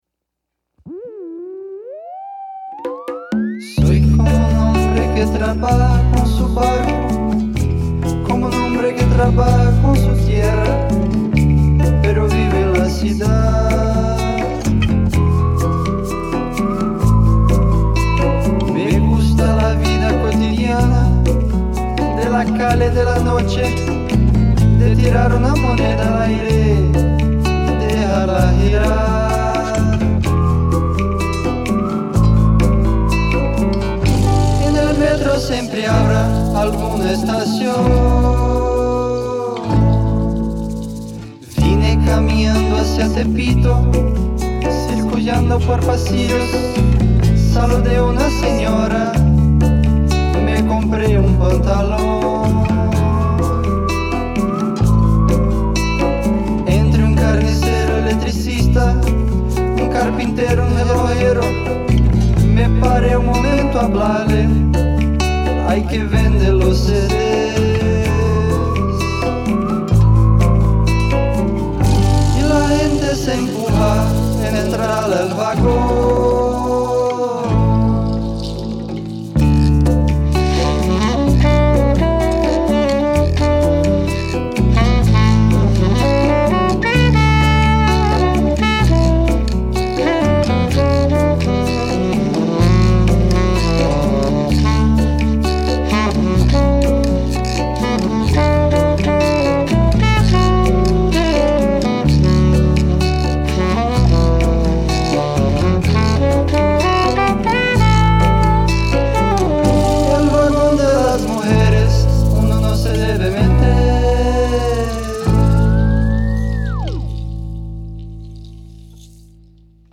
EstiloBossa Nova